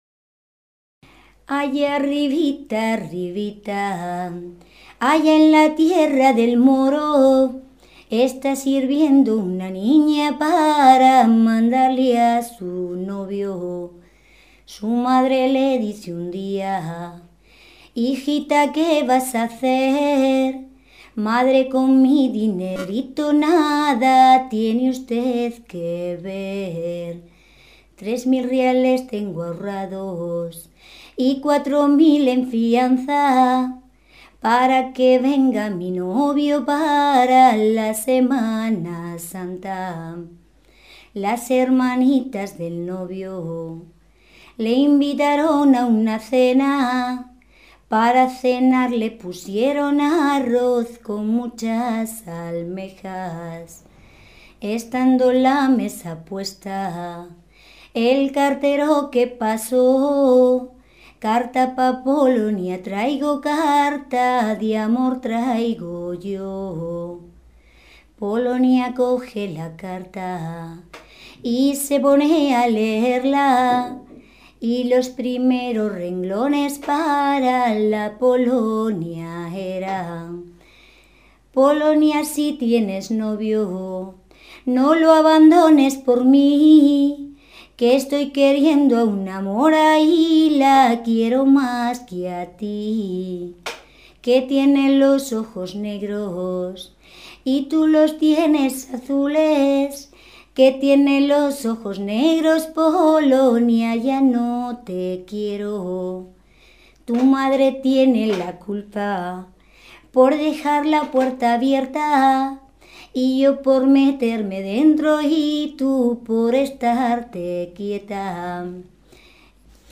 Clasificación: Cancionero, romancero
Lugar y fecha de recogida: Alfaro, 12 de enero de 2001